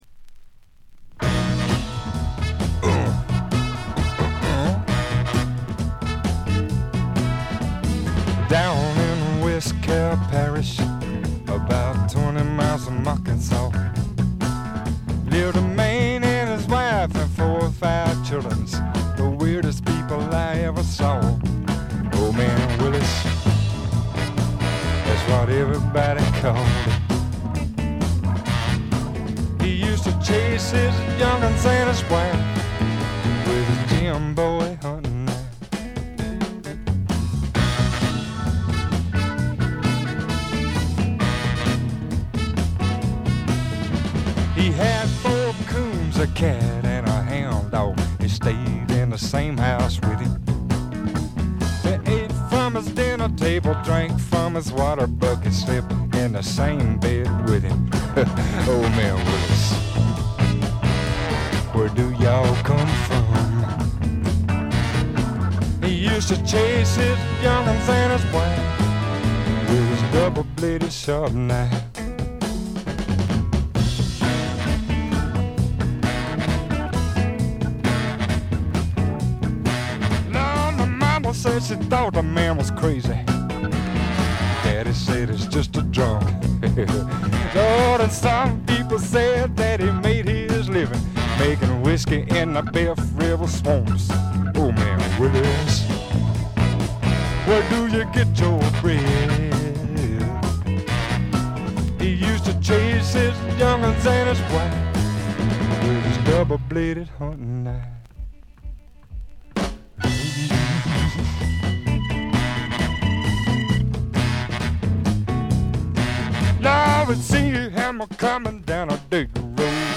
軽微なバックグラウンドノイズ、散発的なプツ音が数か所。
ハードなファンキースワンプから甘いバラードまで、メンフィス録音スワンプ基本中の基本ですね。
試聴曲は現品からの取り込み音源です。
guitar, harmonica